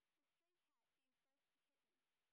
sp16_white_snr20.wav